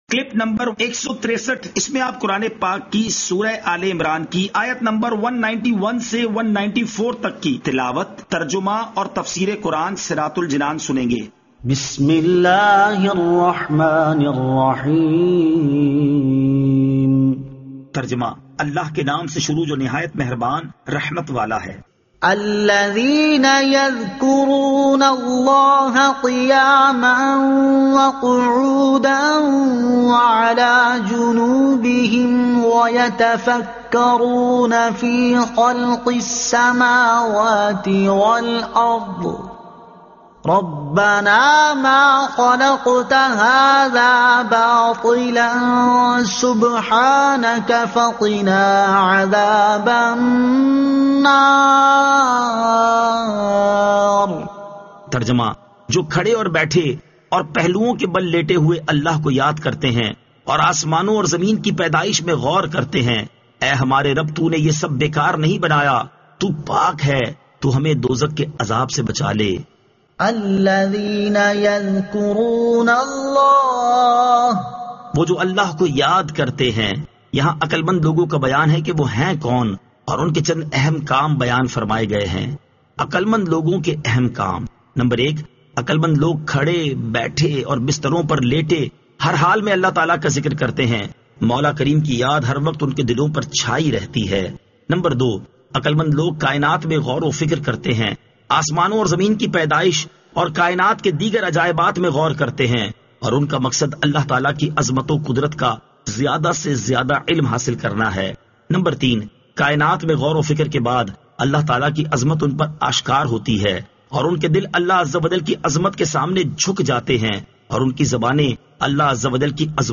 Surah Aal-e-Imran Ayat 191 To 194 Tilawat , Tarjuma , Tafseer